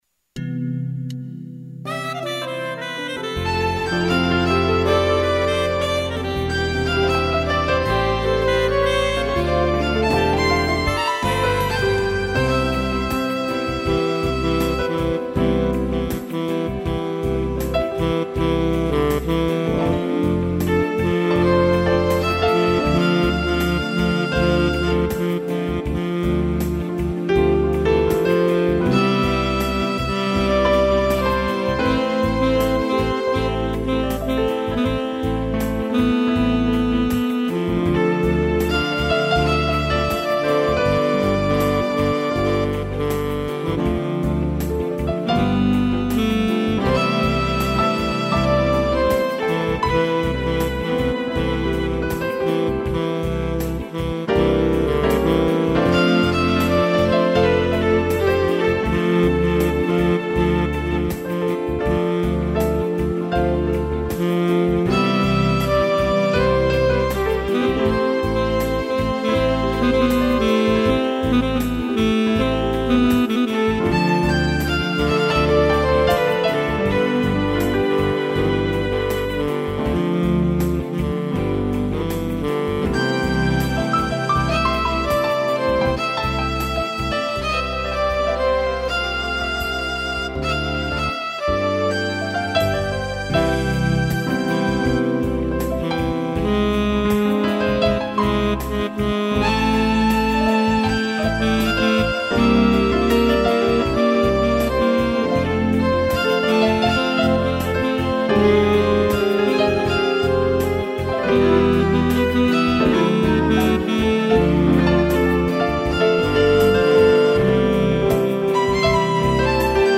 piano e violino
(instrumental)